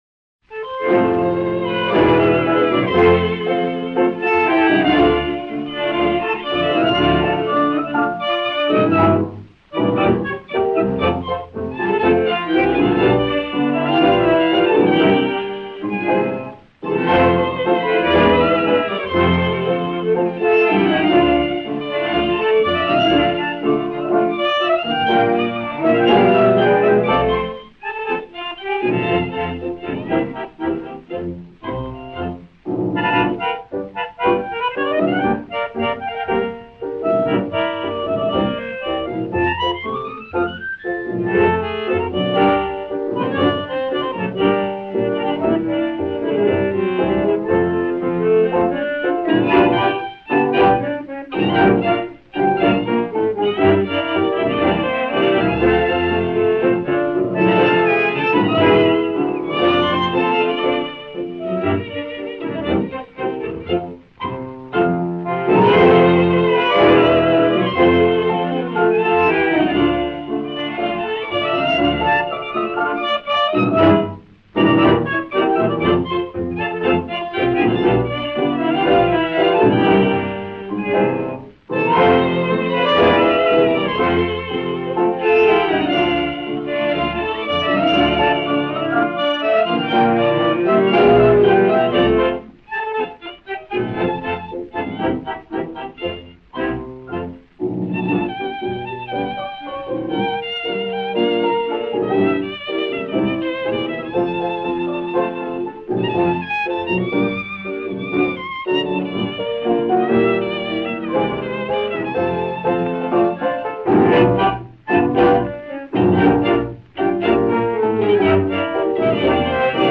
Танго
Instrumental